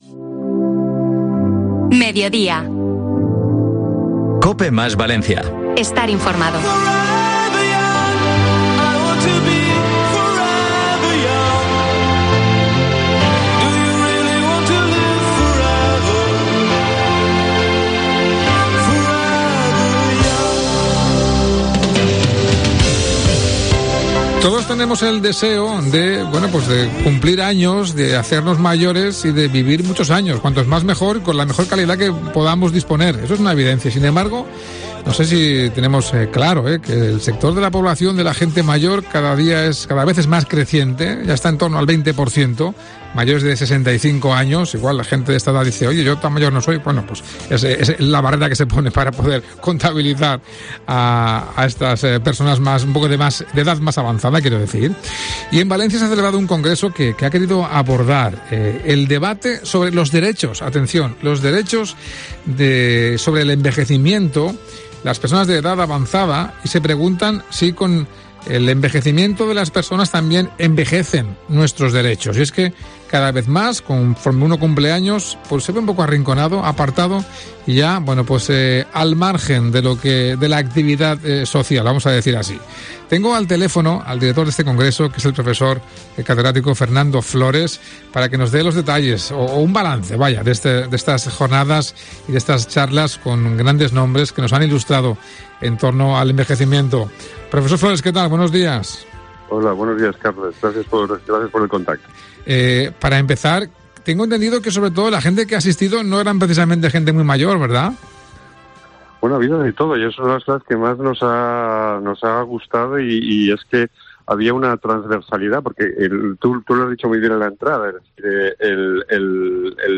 ENTREVISTA | ¿Perdemos derechos cuando envejecemos?